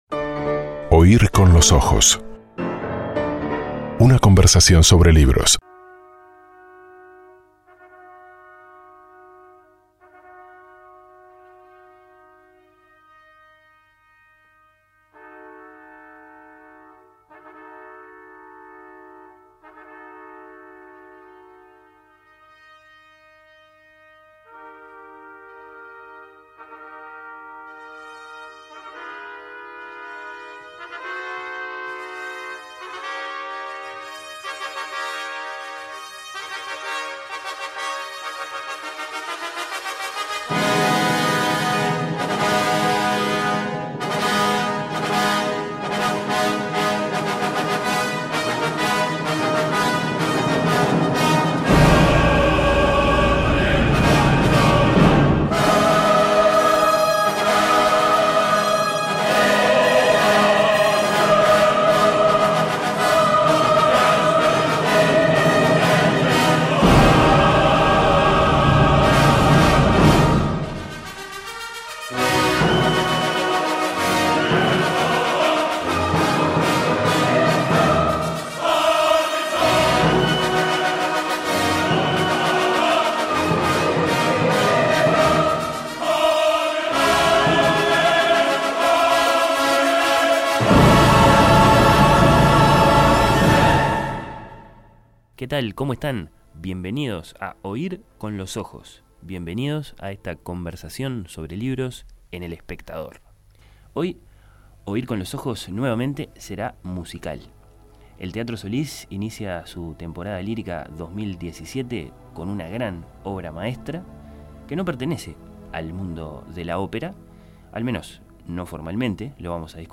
Esta nueva entrega de Oír con los ojos fue musical.